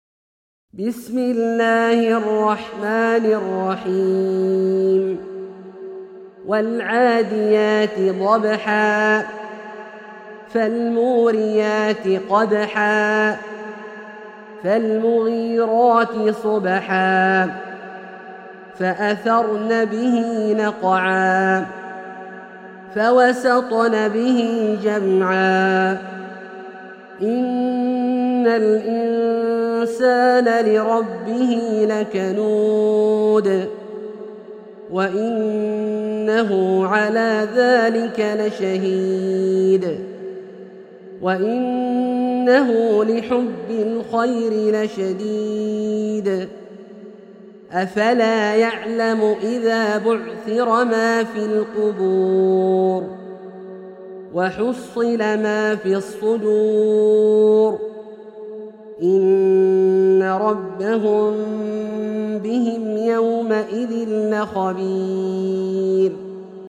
سورة العاديات - برواية الدوري عن أبي عمرو البصري > مصحف برواية الدوري عن أبي عمرو البصري > المصحف - تلاوات عبدالله الجهني